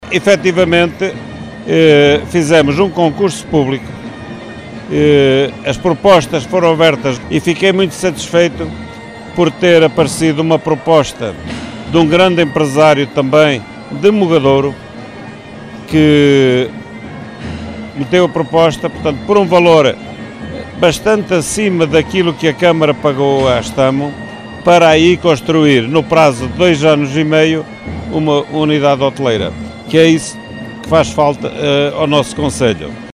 O presidente da Câmara Municipal, António Pimentel, mostrou-se satisfeito com a proposta: